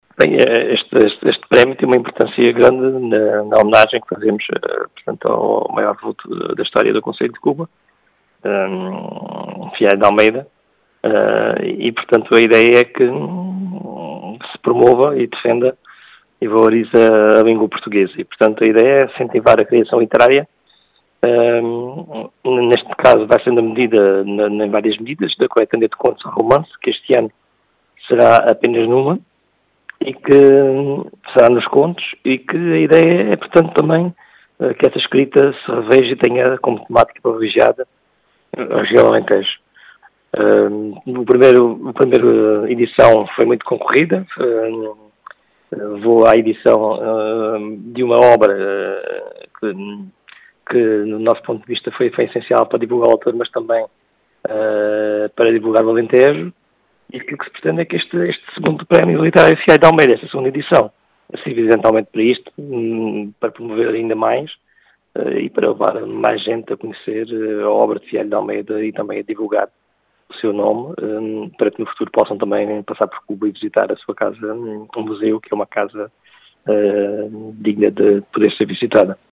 As explicações são do presidente da Câmara Municipal de Cuba, João Português, que diz ser uma “homenagem ao maior vulto da história do concelho”, ao mesmo tempo que pretende “incentivar” a criação literária.